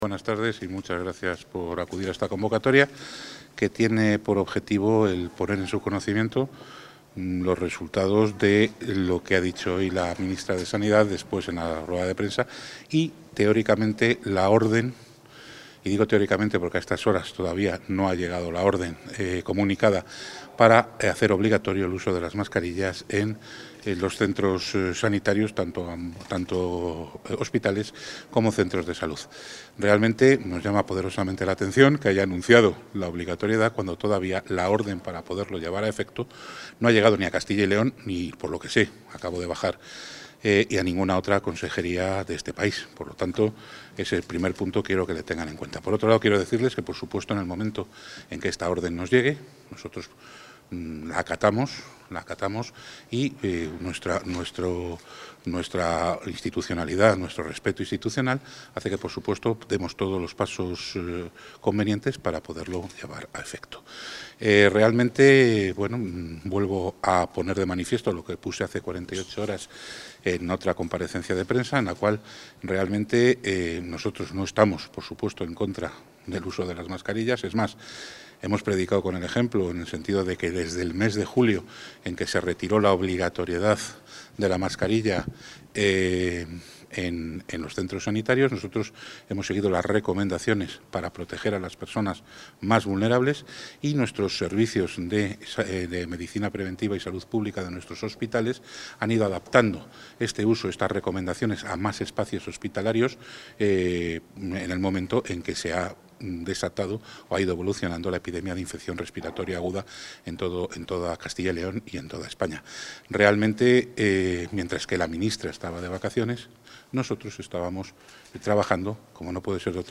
Valoración del consejero de Sanidad sobre la obligatoriedad de las mascarillas en los centros sanitarios | Comunicación | Junta de Castilla y León
Valoración del consejero de Sanidad sobre la obligatoriedad de las mascarillas en los centros sanitarios Contactar Escuchar 10 de enero de 2024 Castilla y León | Consejería de Sanidad El consejero de Sanidad, Alejandro Vázquez Ramos, valora la decisión del Ministerio de Sanidad de obligar al uso de las mascarillas en los centros sanitarios.